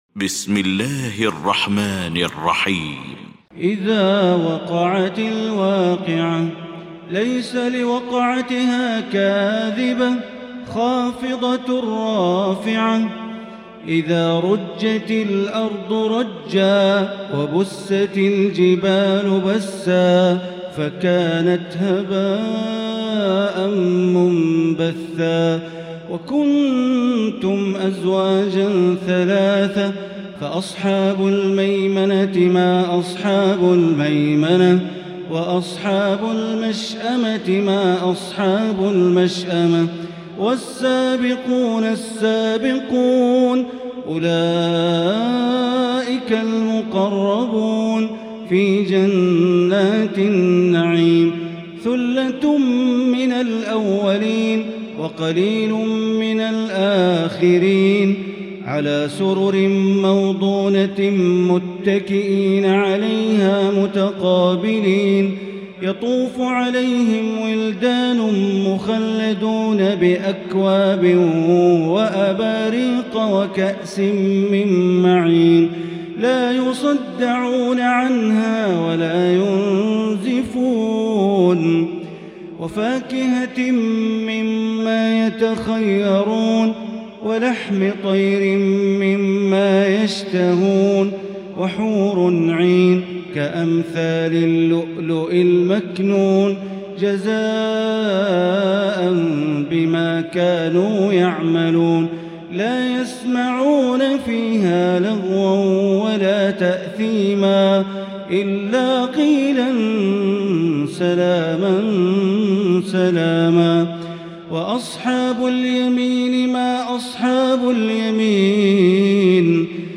المكان: المسجد الحرام الشيخ: معالي الشيخ أ.د. بندر بليلة معالي الشيخ أ.د. بندر بليلة الواقعة